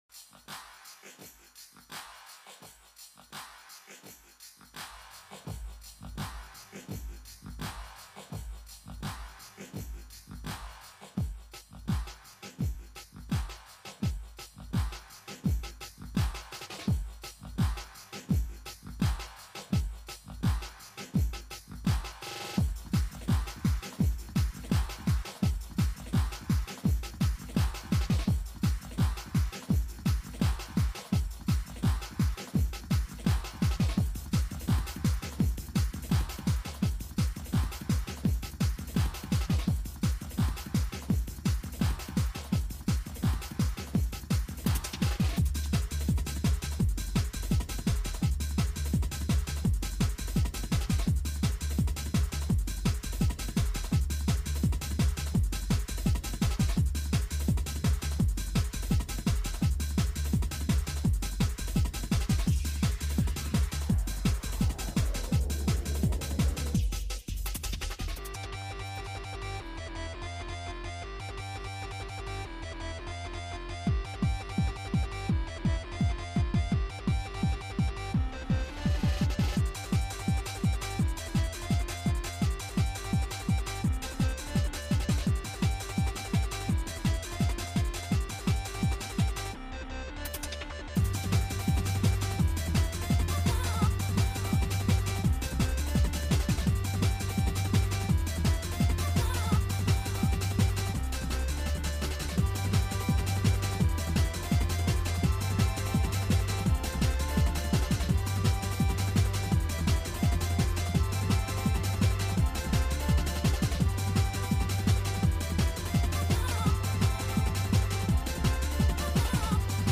Breakbeat Hardcore Oldskool